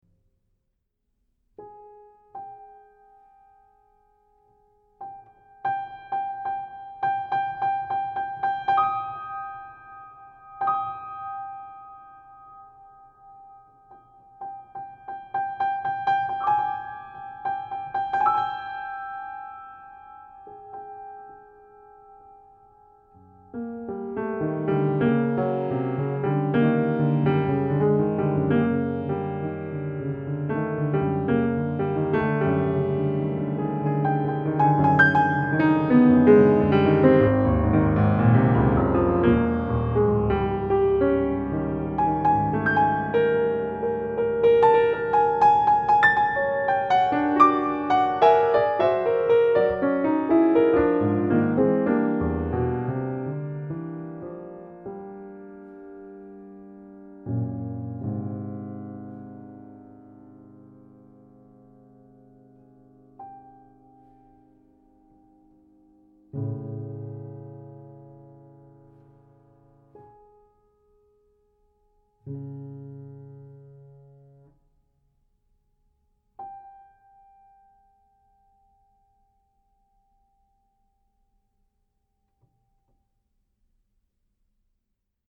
Piano studio opnamen
With a beautiful low and warm high.
Yamaha C7
The premise is simple, an optimal sound of the piano in which the music remains as transparent and expressive as possible.
Piano studio Recordings
Tunes are recorded in our studio.